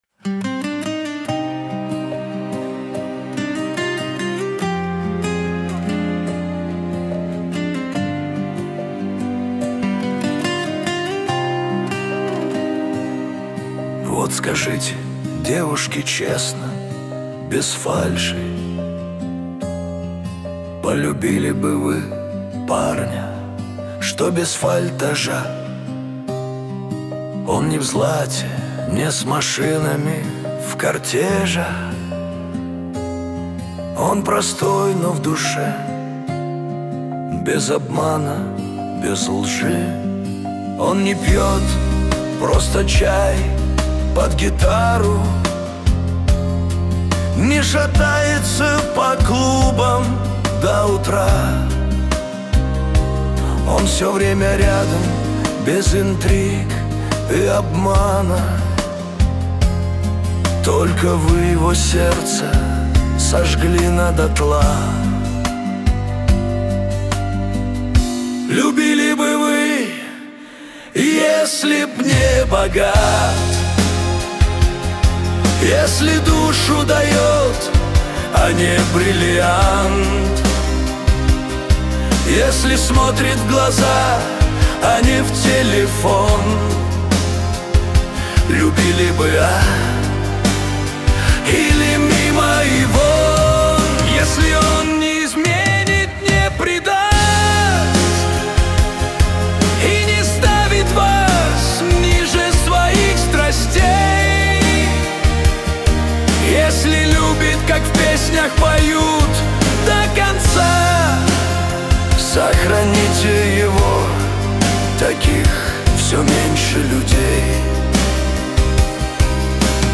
(Remix)